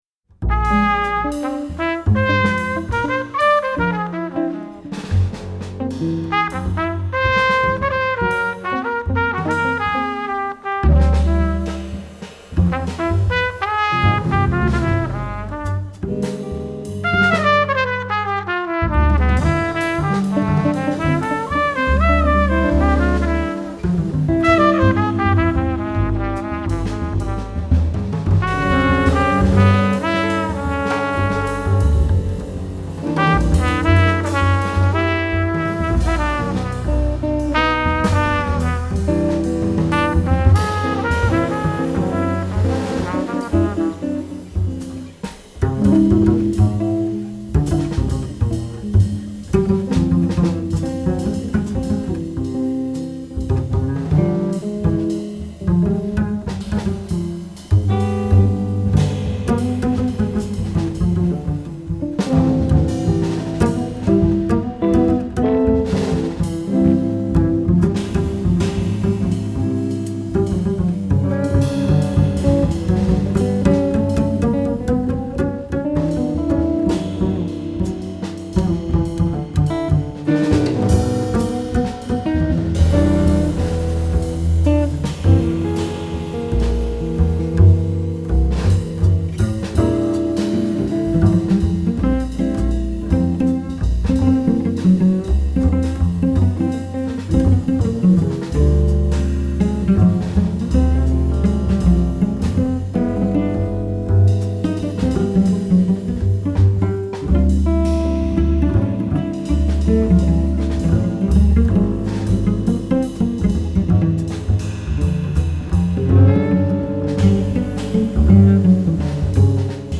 trumpet
guitar
cello
bass
drums
”Nordiskt, lyriskt och klädsamt vingligt”